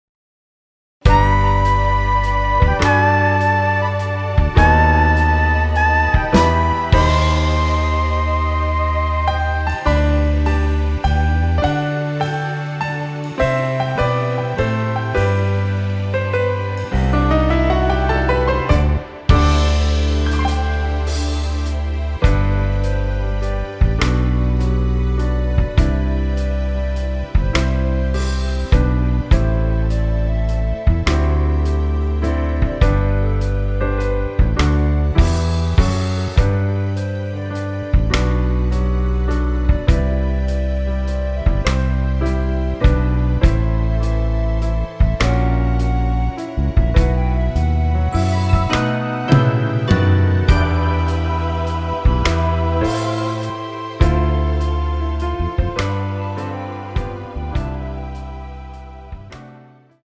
원키 멜로디 포함된 MR입니다.
멜로디 MR이라고 합니다.
앞부분30초, 뒷부분30초씩 편집해서 올려 드리고 있습니다.
중간에 음이 끈어지고 다시 나오는 이유는